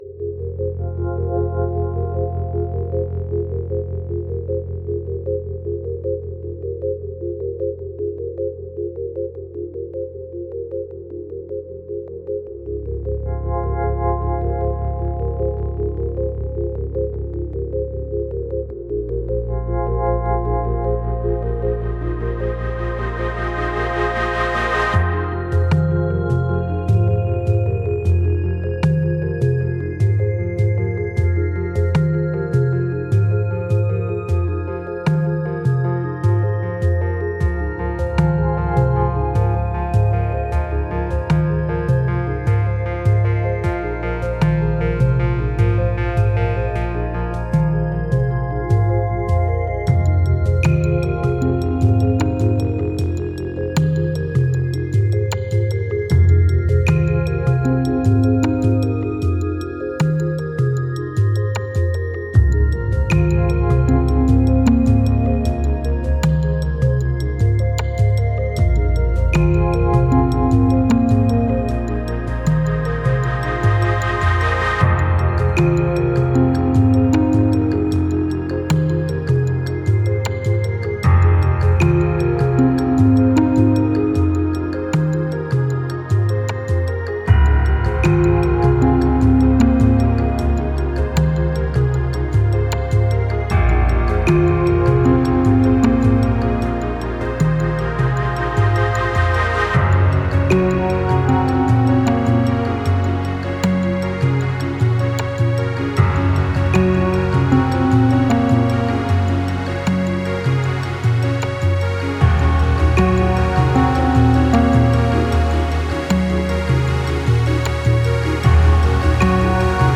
Genre: Soundtrack, Ambient.